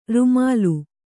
♪ rumālu